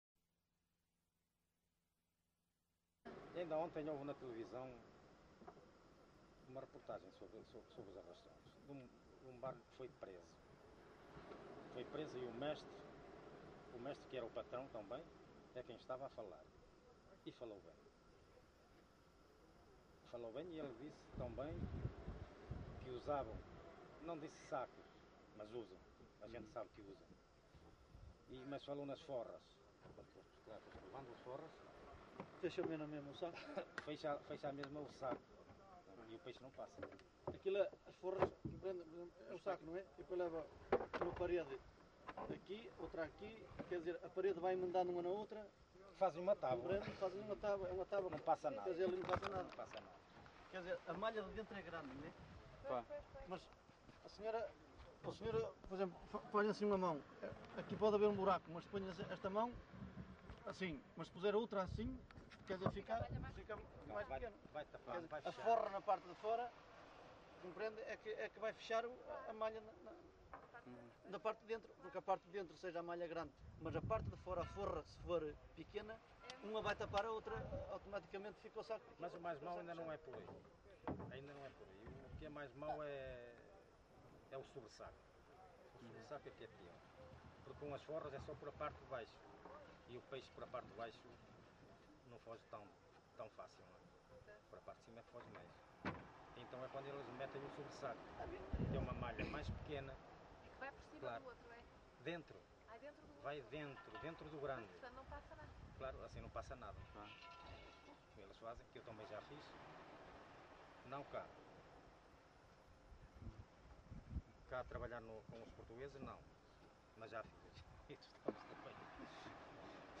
LocalidadeVila Praia de Âncora (Caminha, Viana do Castelo)